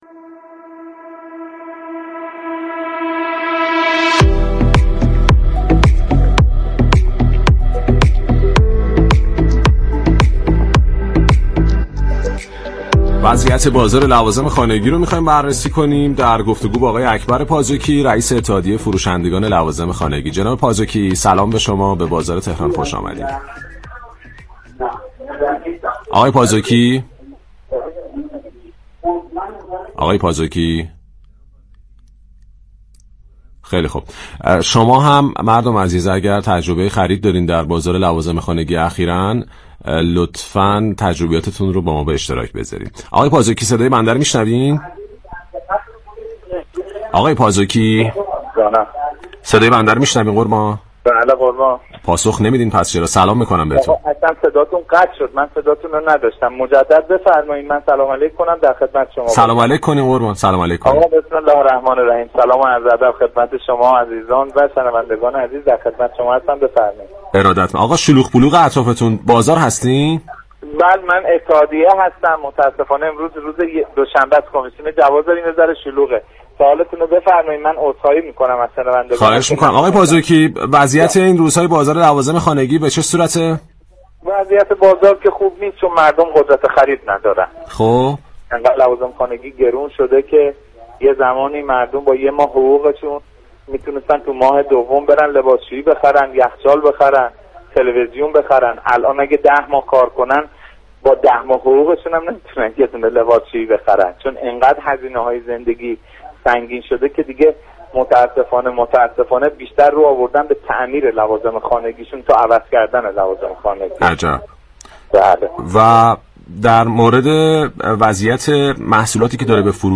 در گفت و گو با بازار تهران رادیو تهران